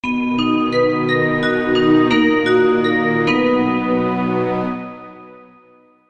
Звуковой сигнал рекламного блока